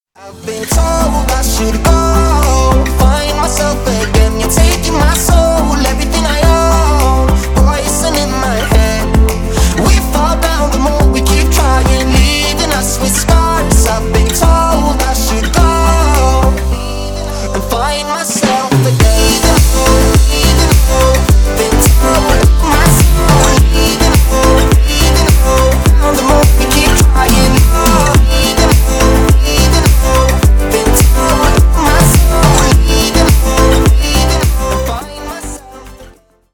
Танцевальные
клубные